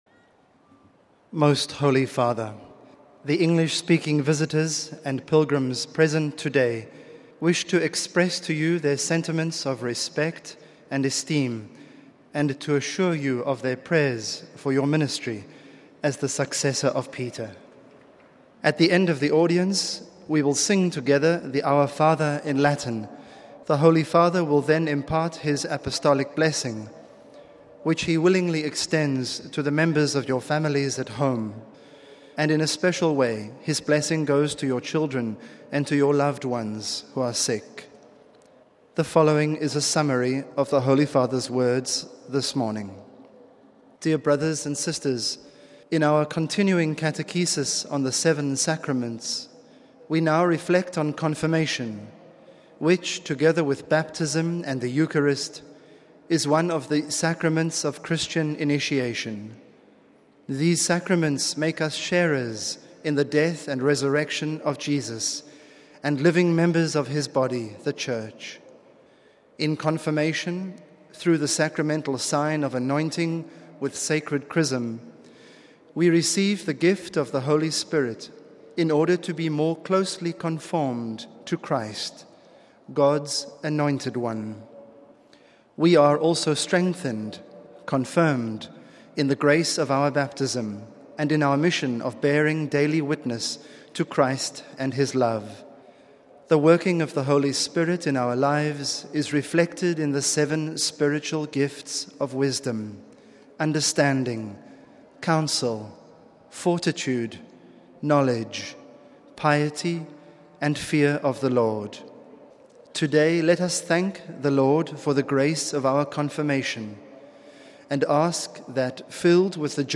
The general audience of Jan. 29 was held in the open, in Rome’s St. Peter’s Square.
Basing himself on the scripture passage, Pope Francis delivered his main discourse in Italian - summaries of which were read out by aides in various languages, including in English. But first, the aide greeted the Pope on behalf of the English-speaking pilgrims.